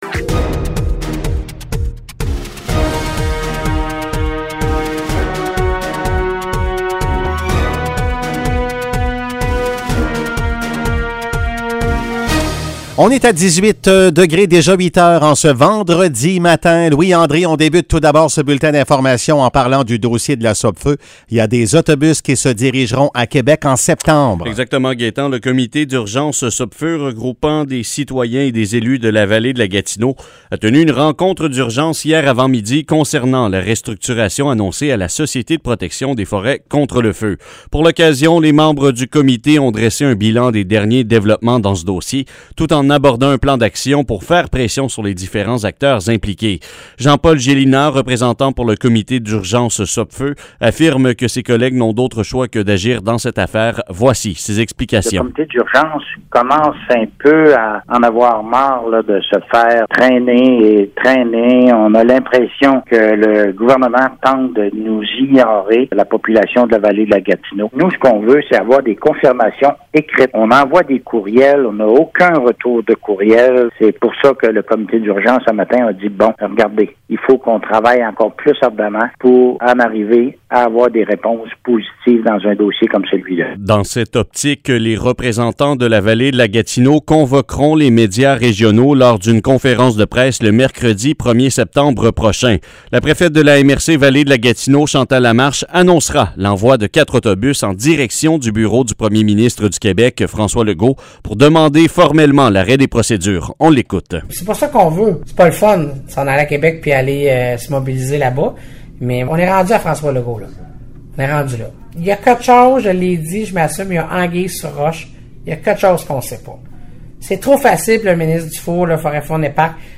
Nouvelles locales - 20 août 2021 - 8 h